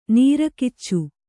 ♪ nīra kiccu